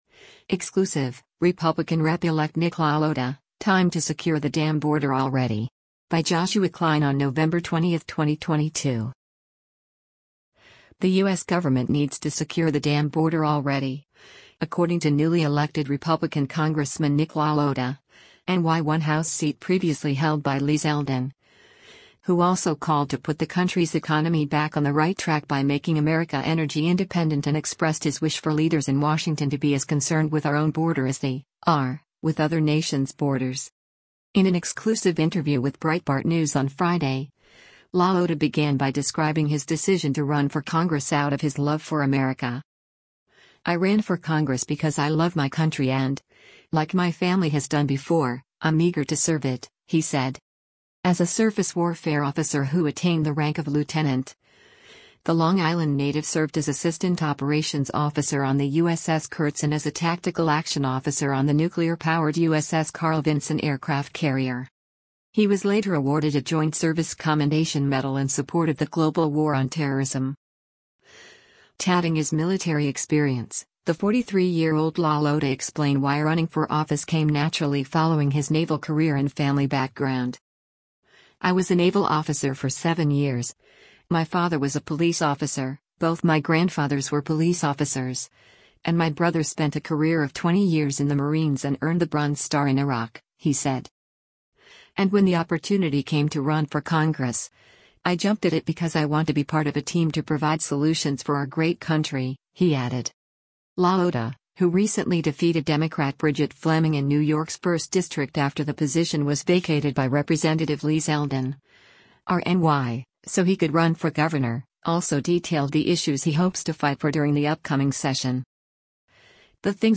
In an exclusive interview with Breitbart News on Friday, LaLota began by describing his decision to run for congress out of his “love” for America.